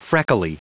Prononciation du mot freckly en anglais (fichier audio)
Prononciation du mot : freckly